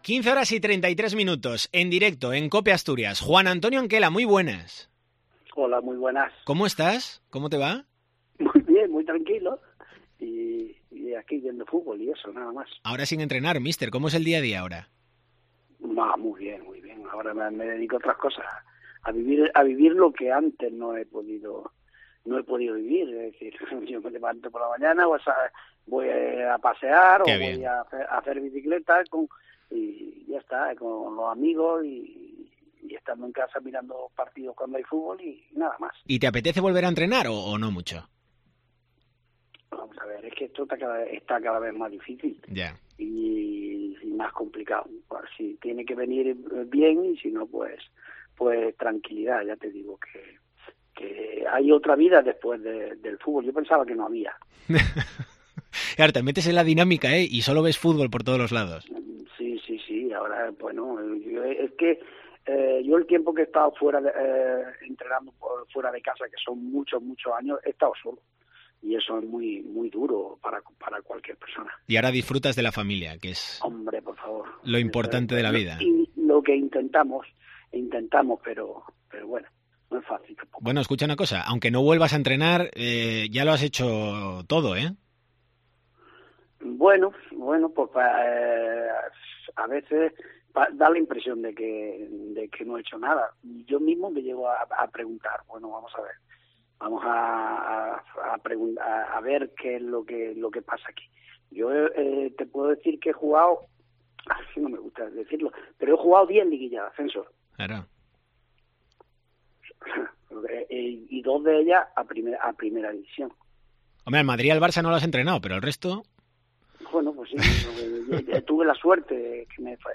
ENTREVISTA COPE ASTURIAS